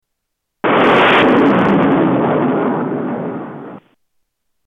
Bomb explosion 1
Category: Sound FX   Right: Personal